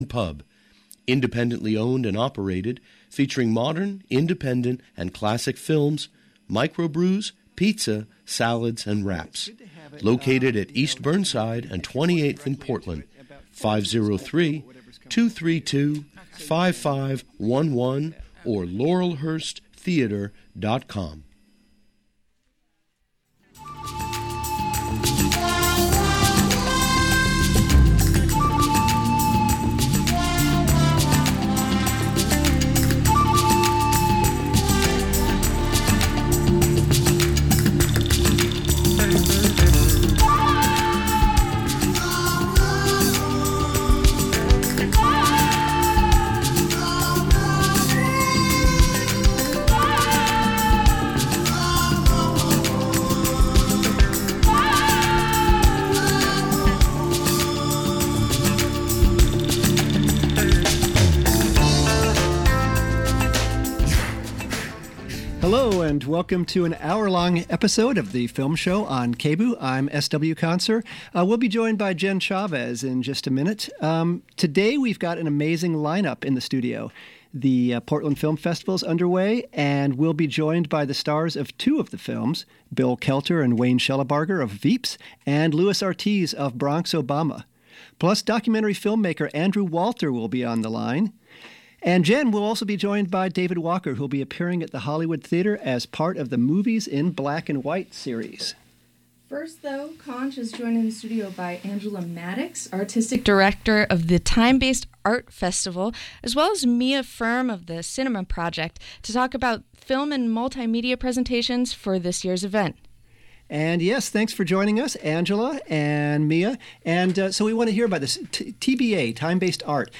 The Film Show features news and interviews about the independent film scene in Portland Every 4th Thursday of the month, from 11:30am to 12:00pm (noon).